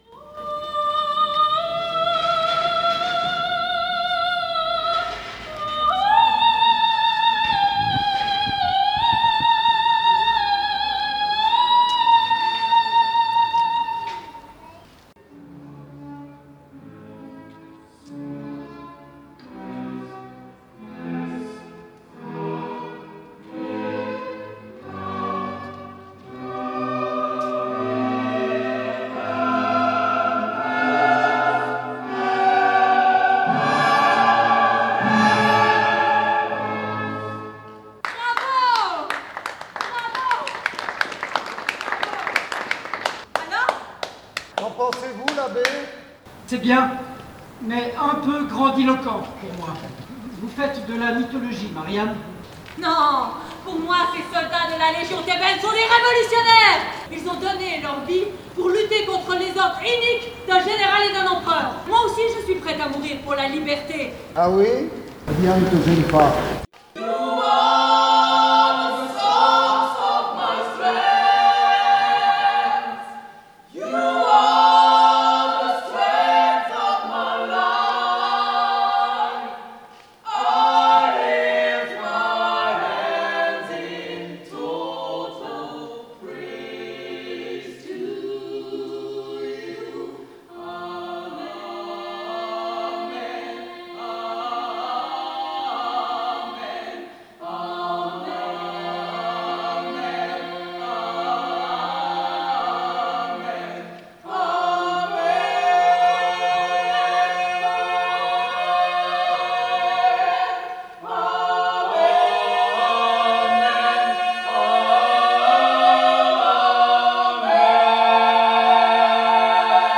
Quelques bribes du spectacle – capturées dans la rue